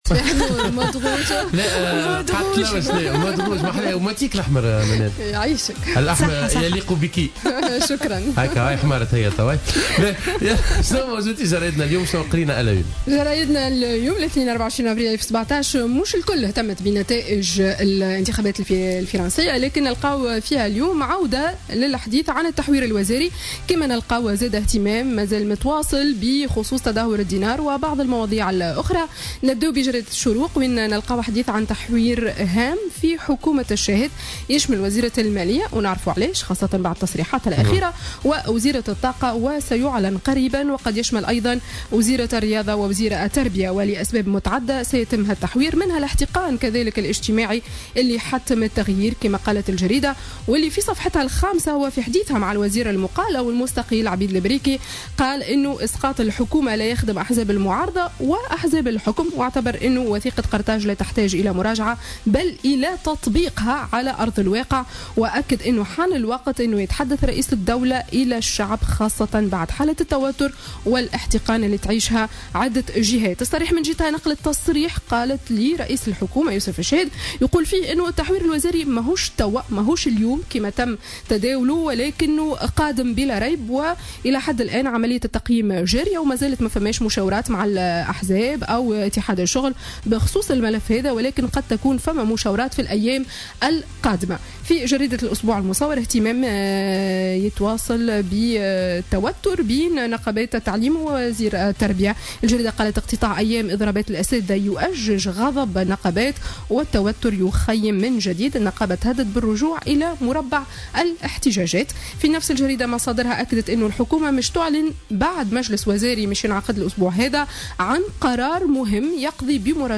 Revue de presse du lundi 24 Avril 2017